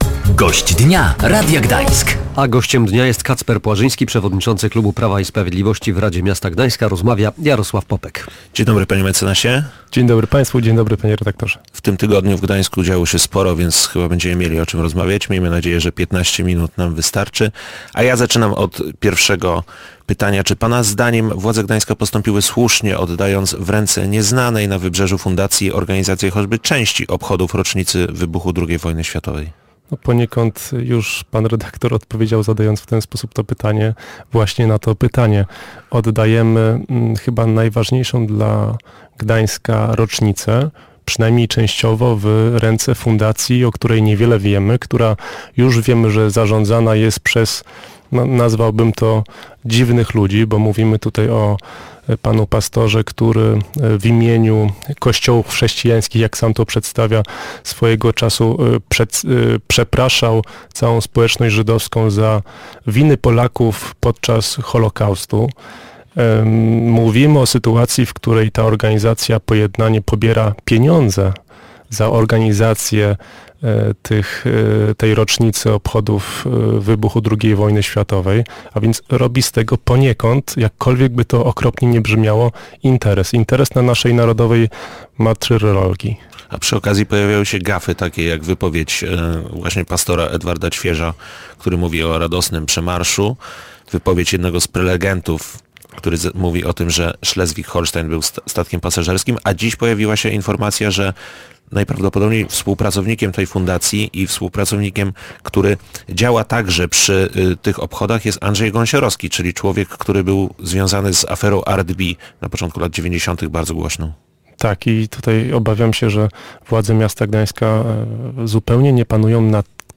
Gościem Dnia Radia Gdańsk był Kacper Płażyński, przewodniczący klubu Prawa i Sprawiedliwości w Radzie Miasta Gdańska.